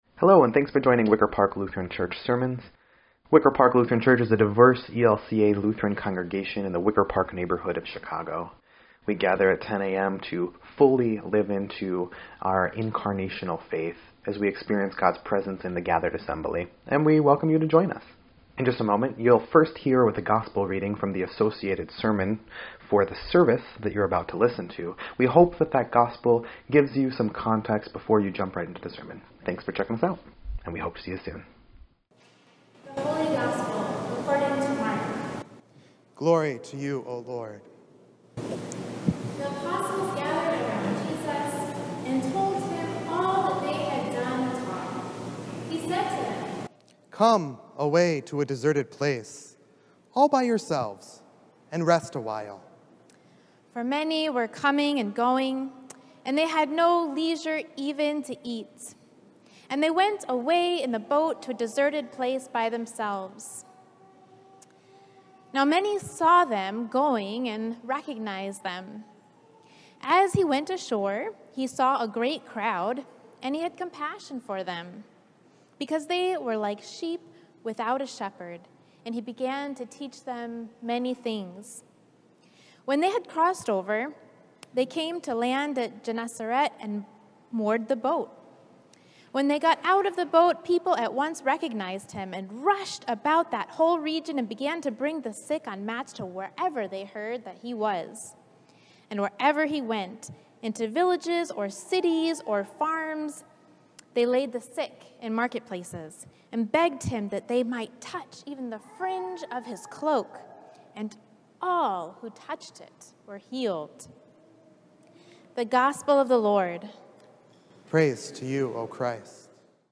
7.18.21-Sermon_EDIT.mp3